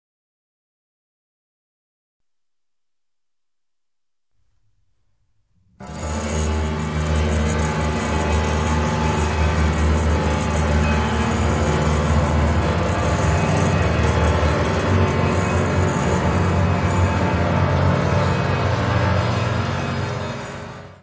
piano
: stereo; 12 cm
Music Category/Genre:  New Musical Tendencies